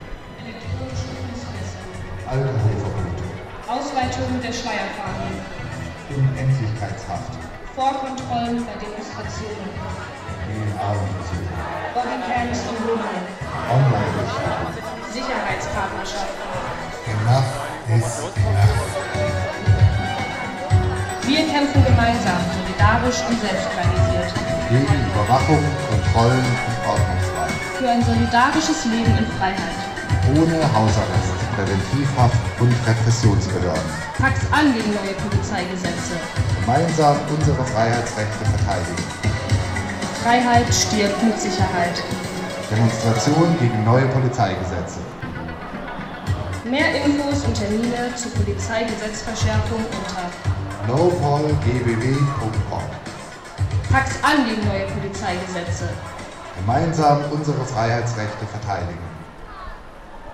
Jingle NOPOLGBW
69616_Jingle_-_NoPolGBw-Org.mp3